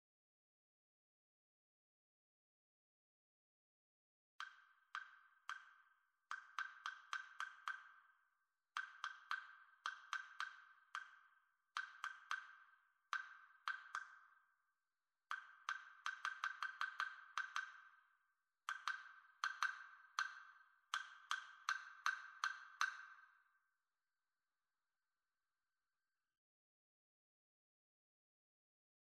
2) Agogos & Castanets
The second piece is more of a friendly conversation between agogos and castanets.
The piece begins rather slowly but constantly becomes faster from bars 3-8. Whilst the agogos start in forte and become quieter in the middle of the piece, the castanets (which started with a pianissimo) are constantly becoming louder.